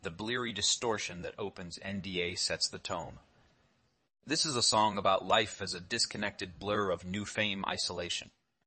to23oise-tts - (QoL improvements for) a multi-voice TTS system trained with an emphasis on quality